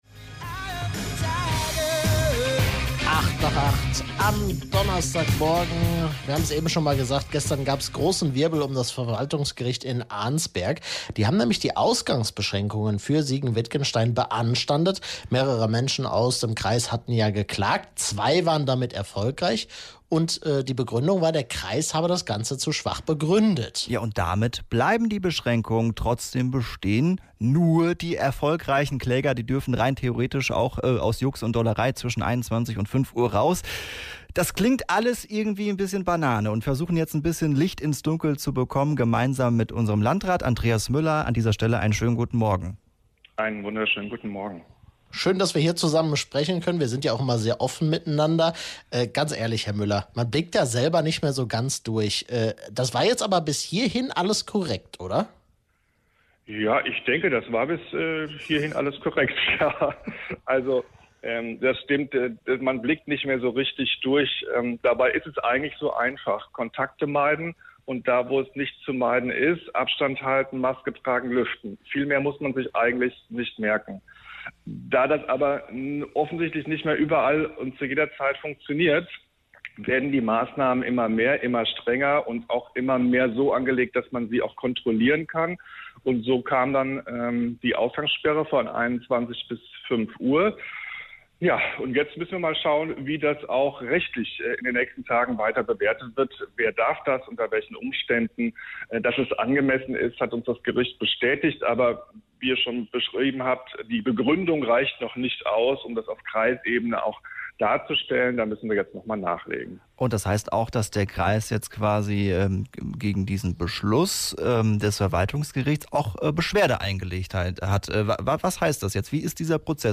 Auswirkungen hat das aber zunächst nur für die Kläger, für alle anderen bleibt die Ausgangssperre bestehen. Landrat Andreas Müller hat das im Radio-Siegen-Interview begründet.